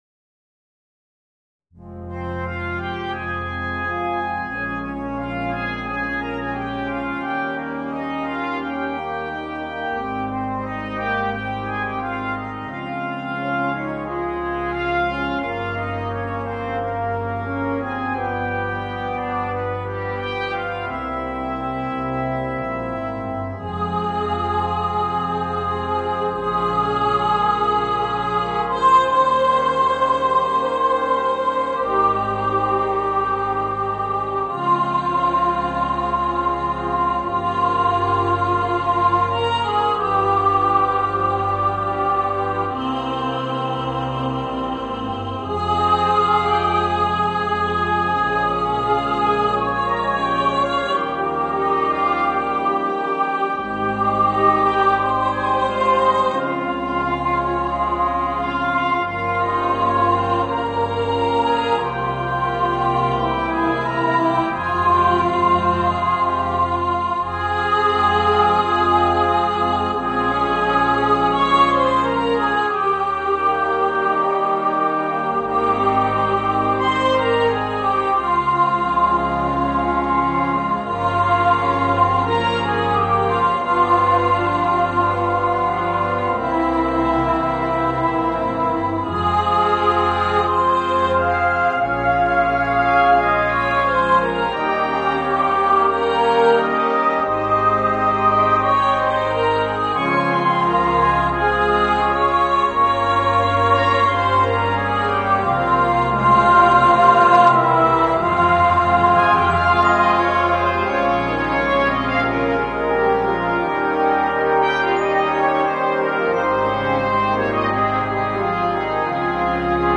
Voicing: Brass Band